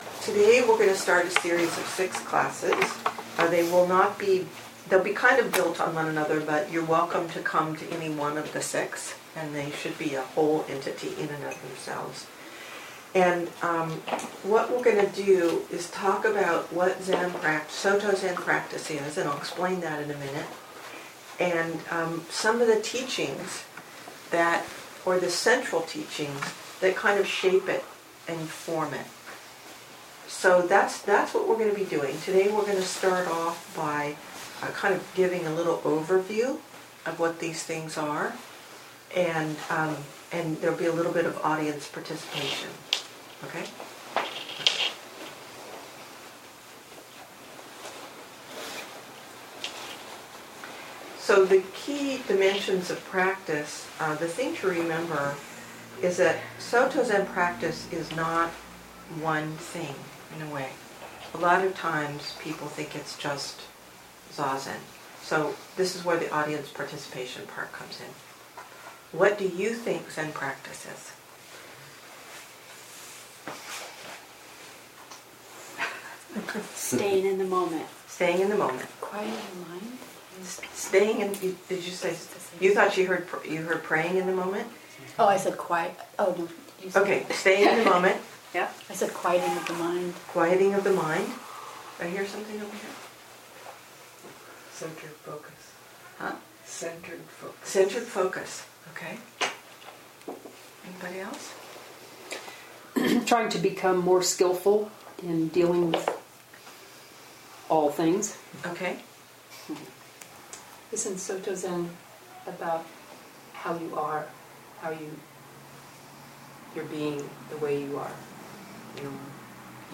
2014 in Dharma Talks